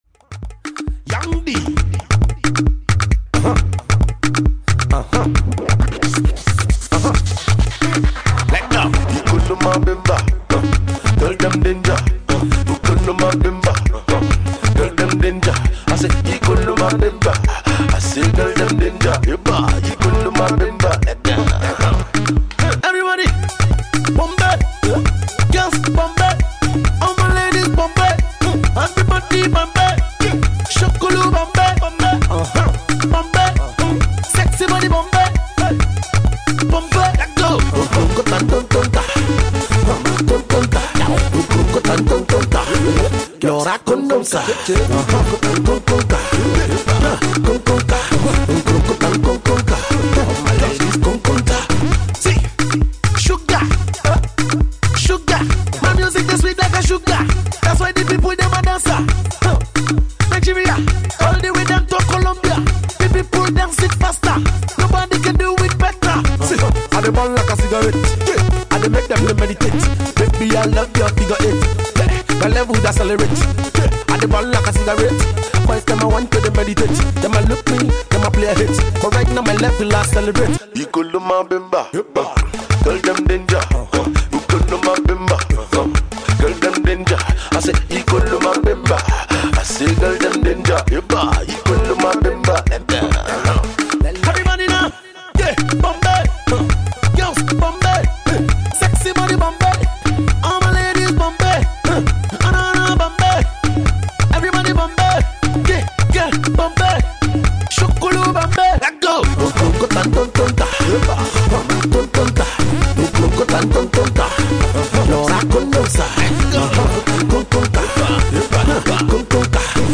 With another funky dance jam
super-catchy